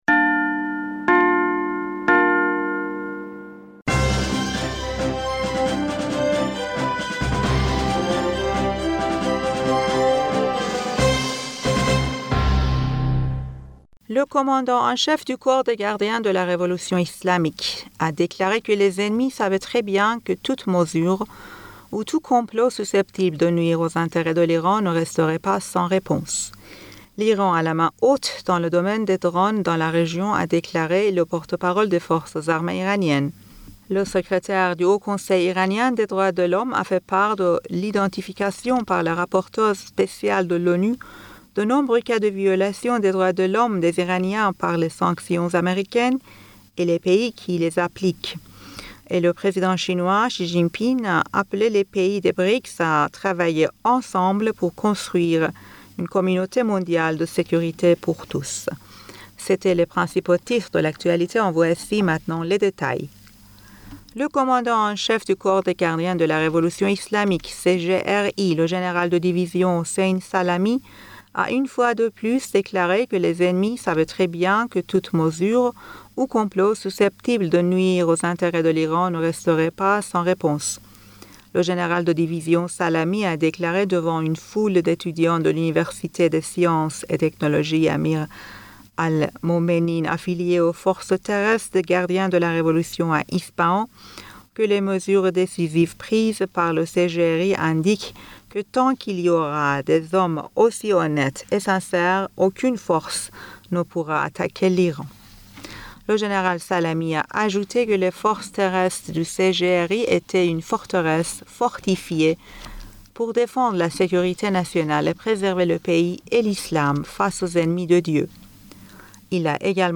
Bulletin d'information Du 20 Mai 2022